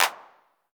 Index of /90_sSampleCDs/Club-50 - Foundations Roland/KIT_xTR909 Kits/KIT_xTR909 3